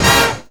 JAZZ STAB 32.wav